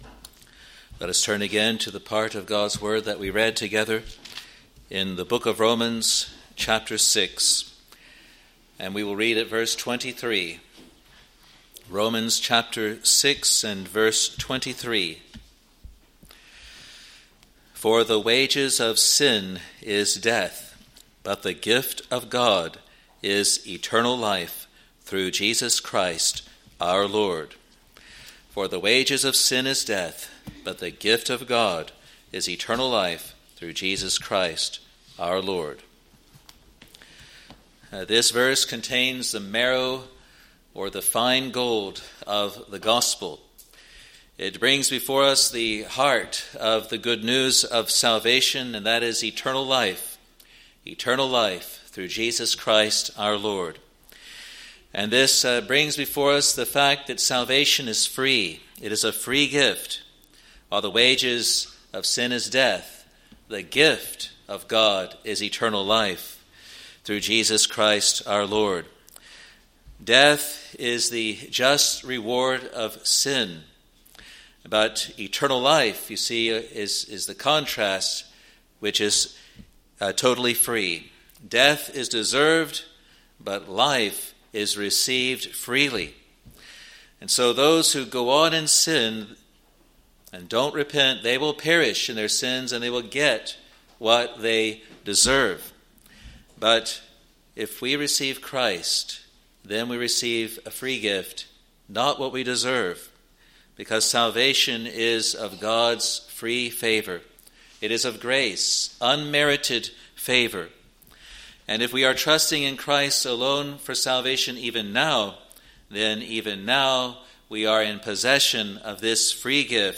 Sermons | Free Presbyterian Church of Scotland in New Zealand